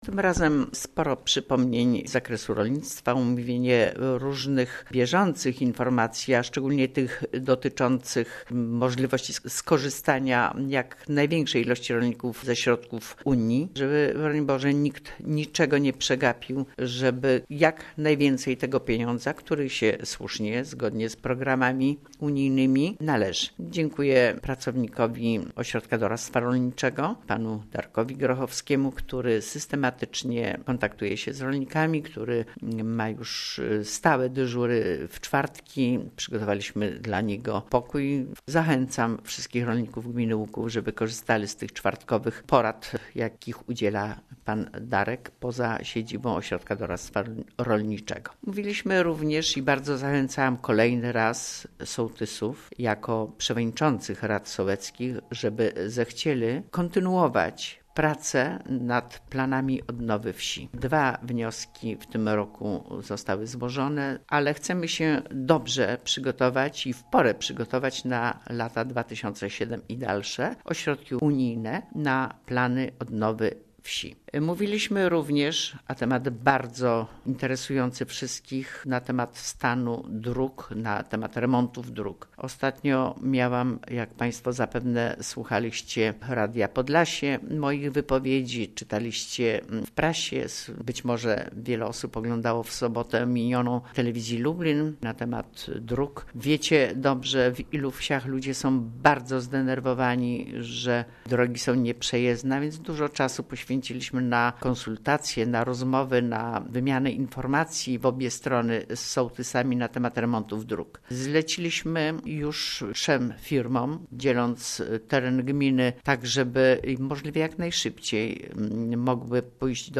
W�jt Gminy �uk�w Kazimiera Go�awska